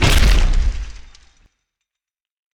player-hit.ogg